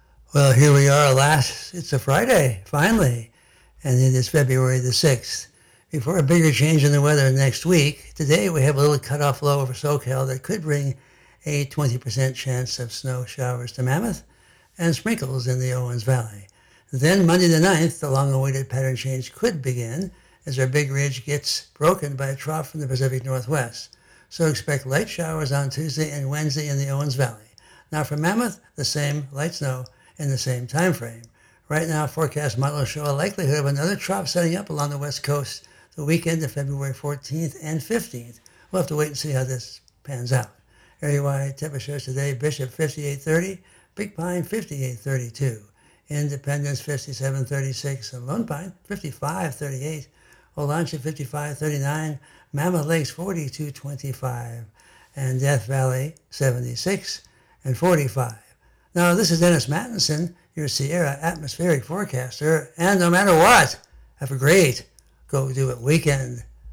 Area Forecast Discussion